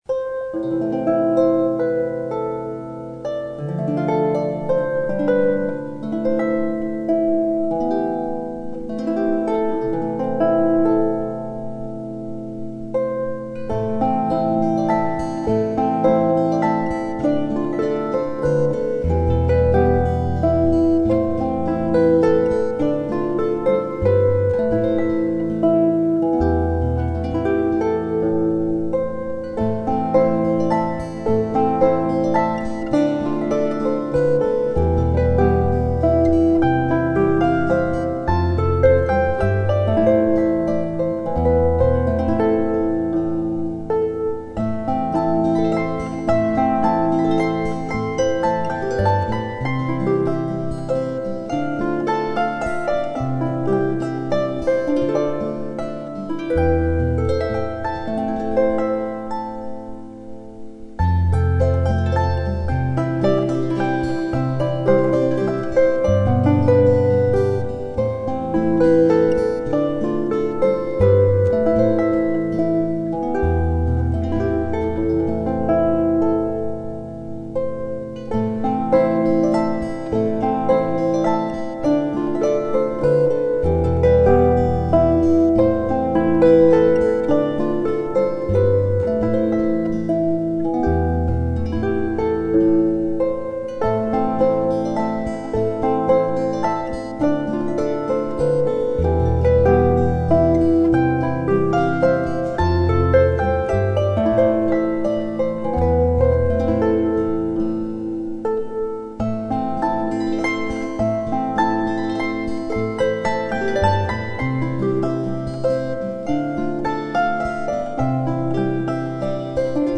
Celtic Twist - traditional Scottish Harp and Guitar duo.
String Musicians